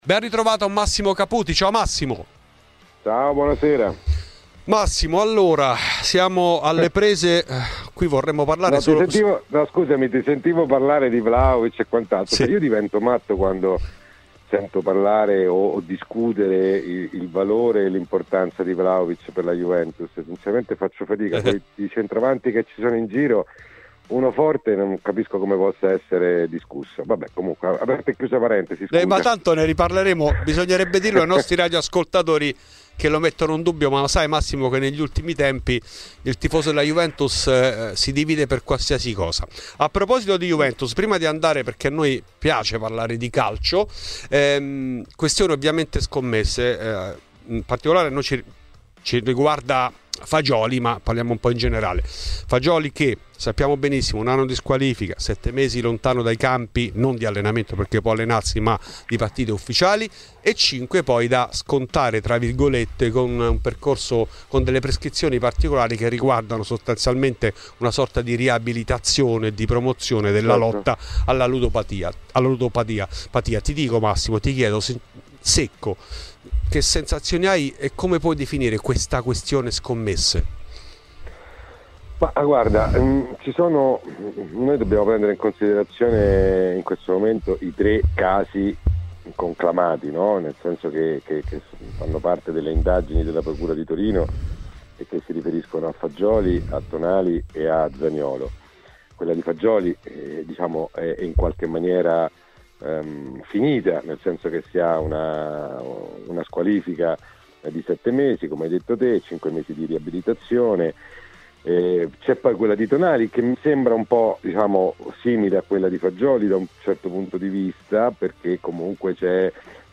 In ESCLUSIVA a Fuori di Juve il giornalista Massimo Caputi.